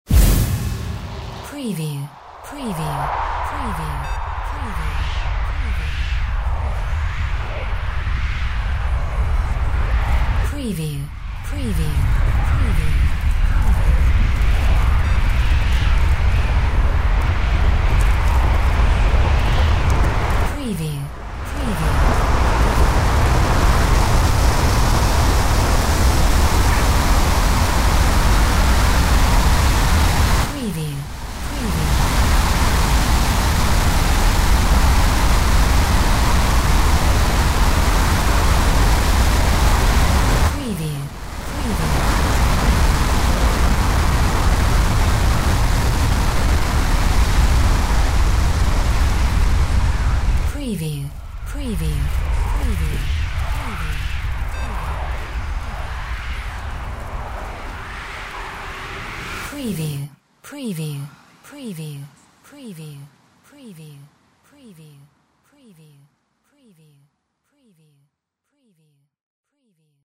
Wind Magic Full 01B
This is a slightly different mix to the first. More wind elements added and more impact on the the initial envelope of the sound.
Stereo sound effect - Wav.16 bit/44.1 KHz and Mp3 128 Kbps
previewSCIFI_MAGIC_WIND_FULL_WBHD01B.mp3